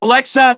synthetic-wakewords
ovos-tts-plugin-deepponies_Scout_en.wav